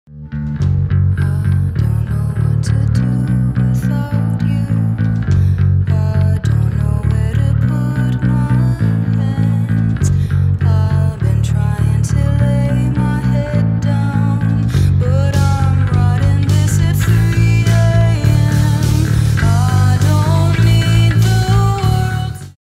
Bass Cover
Tuning: D A D G